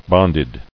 [bond·ed]